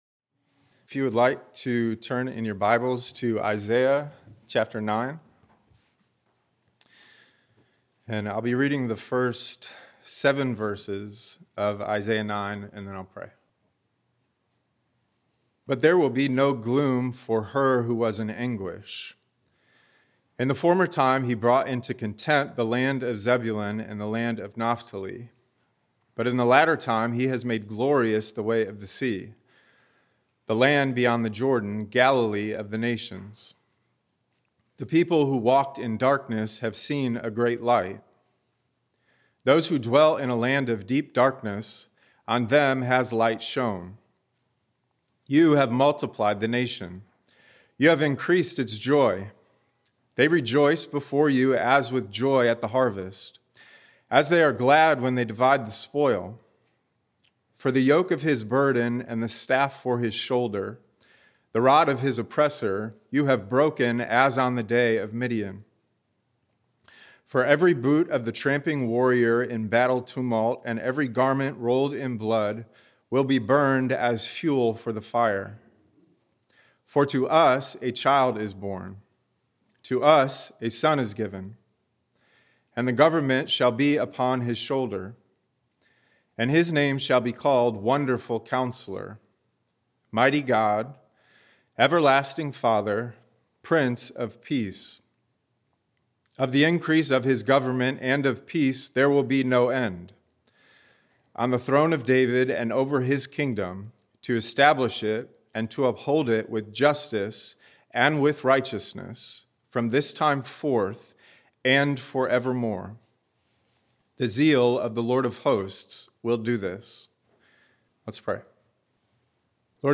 A message from the series "Advent 2024."